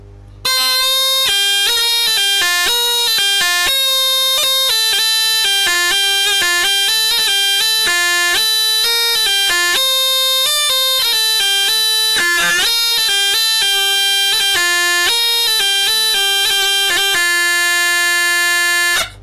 Звучит очень громко, как-то
Звучит, как мини-волынка.
Судя по звуку весьма хорошая жалеечка.
zhaleika.mp3